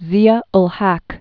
(zēə l-hăk, -häk), Mohammad 1924-1988.